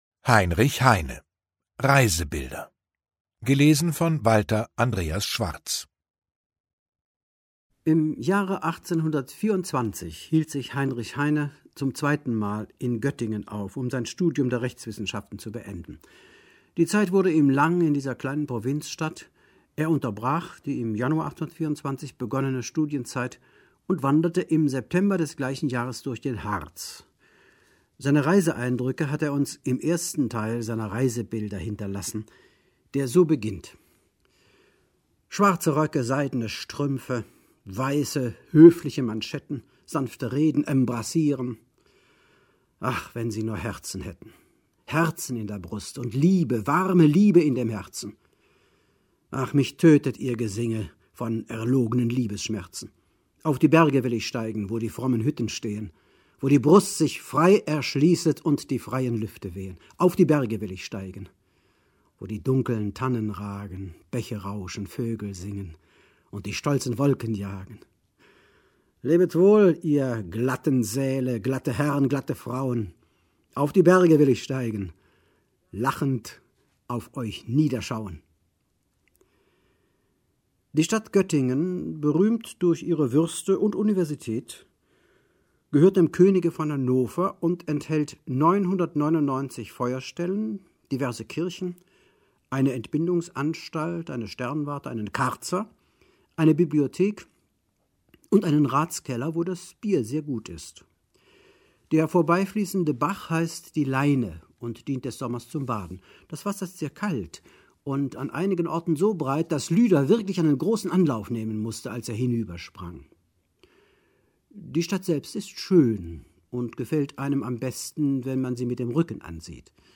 Walter Andreas Schwarz (Sprecher)
2021 | Gekürzte Lesung
Walter Andreas Schwarz gibt den zum Teil spöttischen Ton Heines pointiert wieder.